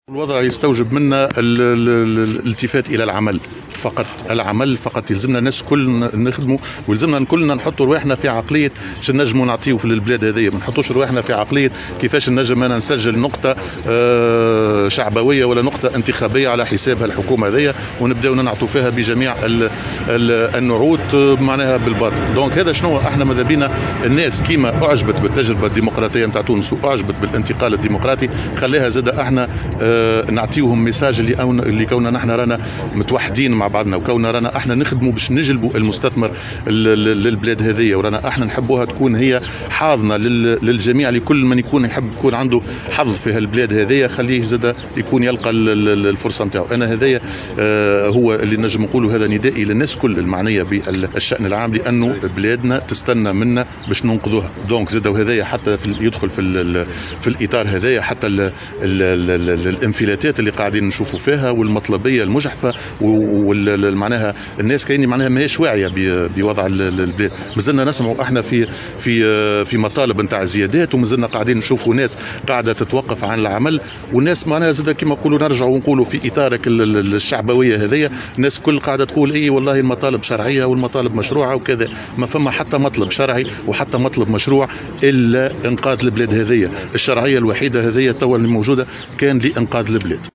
خلال إشرافه على تسليم هبة أمريكيّة لفائدة وزارة الدّاخليّة بحضور سفير الولايات المتّحدة الأمريكيّة، بمقرّ الإدارة العامّة للحرس الوطني بالعوينة